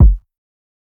Metro Mean Kick.wav